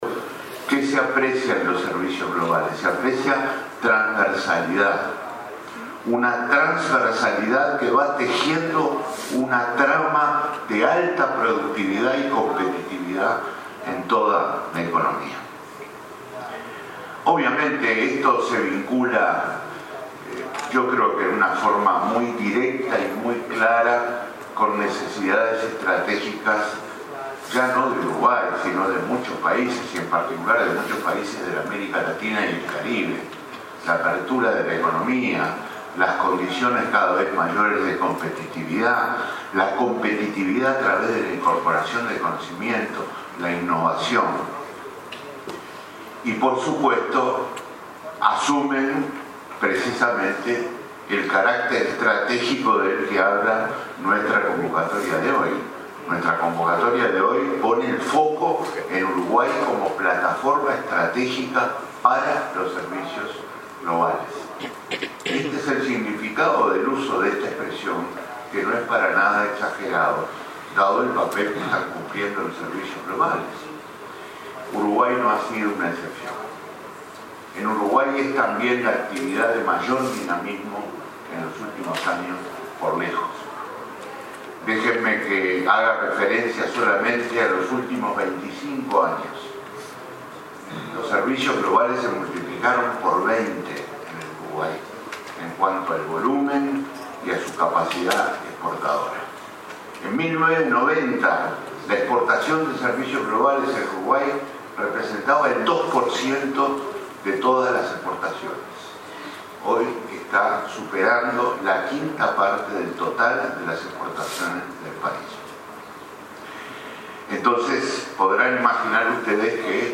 Los servicios globales se multiplicaron por 20 en Uruguay en los últimos 25 años, en cuanto a volumen y capacidad exportadora, subrayó el ministro Danilo Astori en el evento Uruguay Business Experience 2017, organizado por Uruguay XXI. El jerarca sostuvo que nuestro país ofrece un buen clima de negocios para promover las inversiones, basado en pilares como institucionalidad, estímulos económicos y orden macroeconómico.